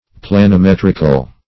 planimetrical - definition of planimetrical - synonyms, pronunciation, spelling from Free Dictionary